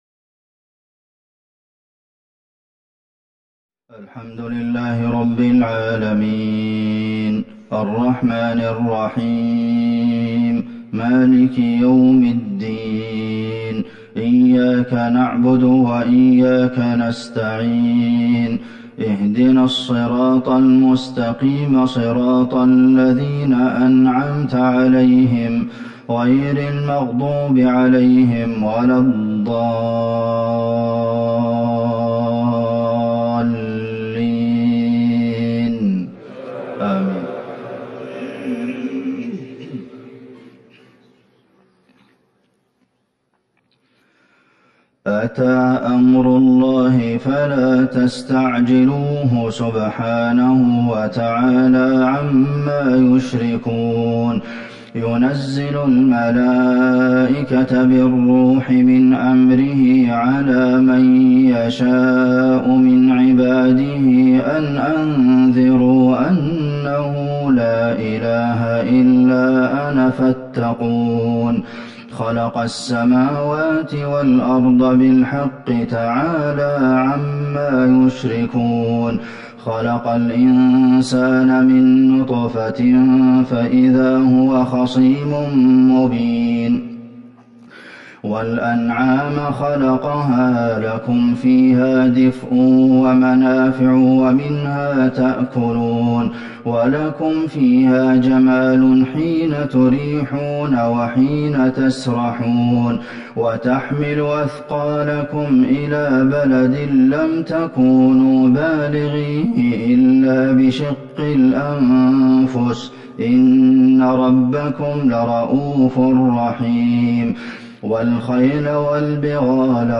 صلاة الفجر ١٠ جمادي الاخره ١٤٤١هـ سورة النحل Fajr prayer 4-2-2020 from Surah An-Nahl > 1441 🕌 > الفروض - تلاوات الحرمين